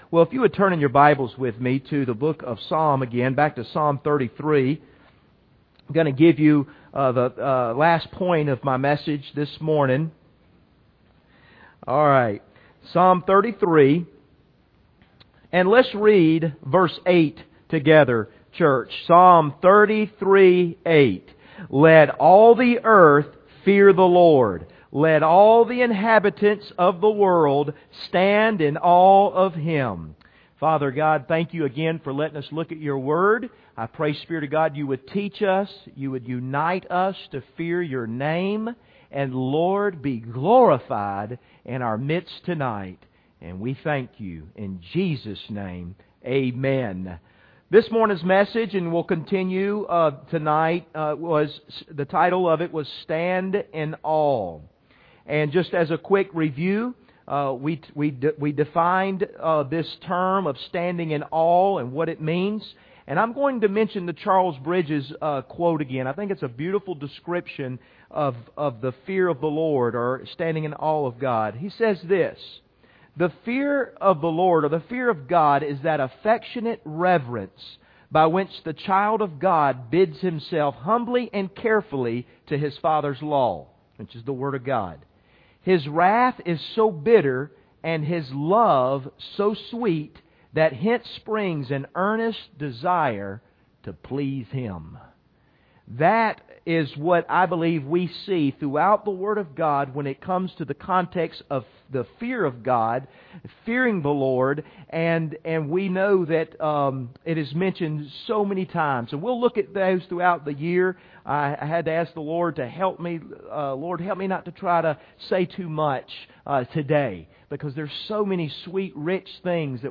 Psalms33:8 Service Type: Sunday Evening « How Do You Respond When Your Expectations Are Not Met?